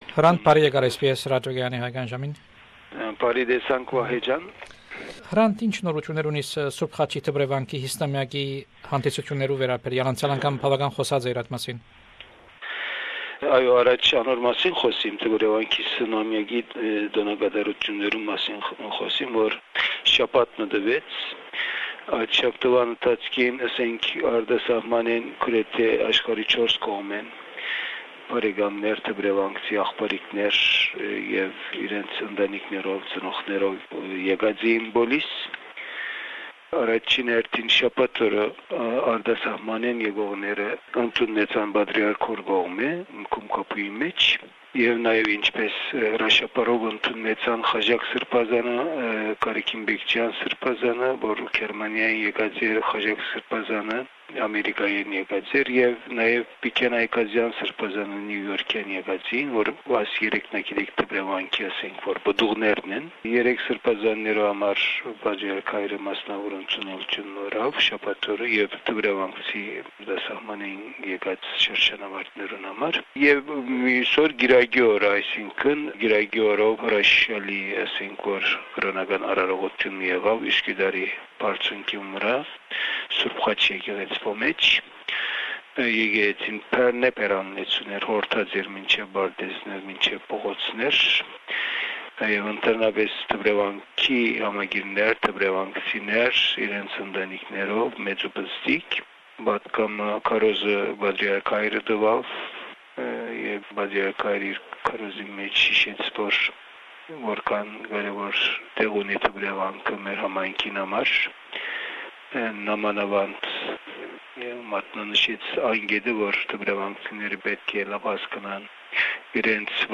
This is the fifth interview recorded in September 2003.
Hrant Dink at SBS Radio Source